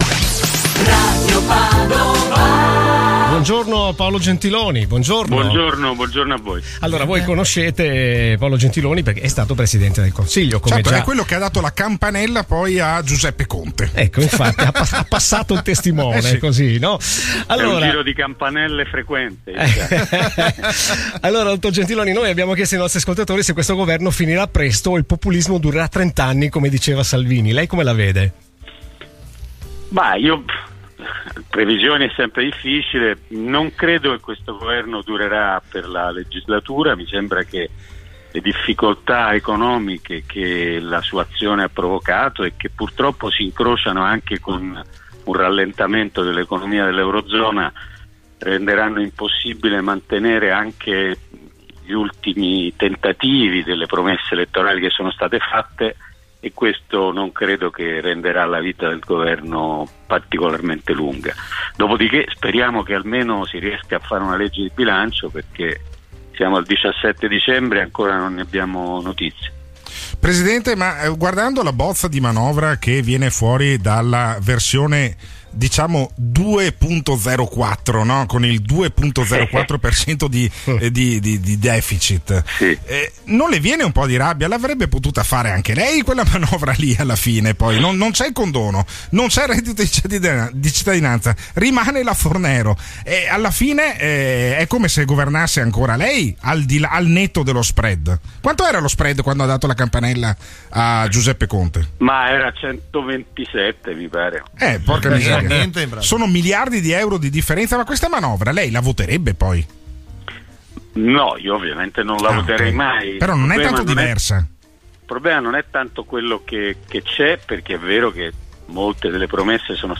qui sotto l’intervista a Paolo Gentiloni andata in onda stamattina su Radio Padova